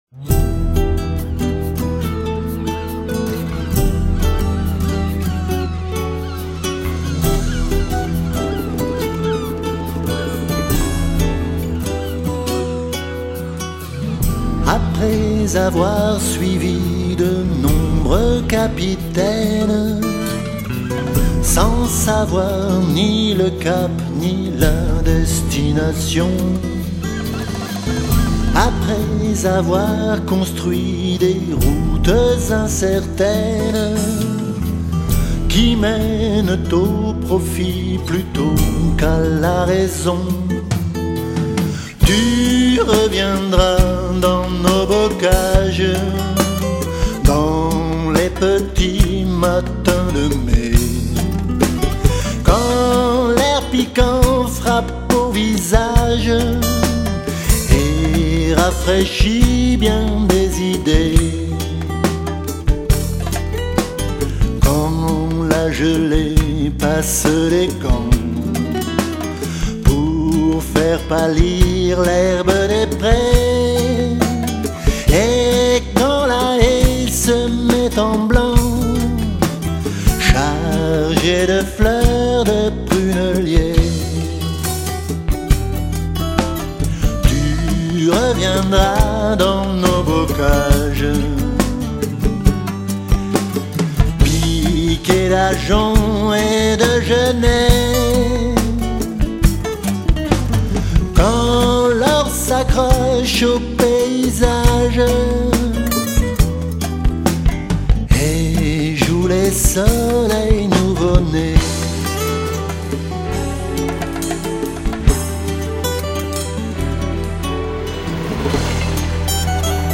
chante 13 titres originaux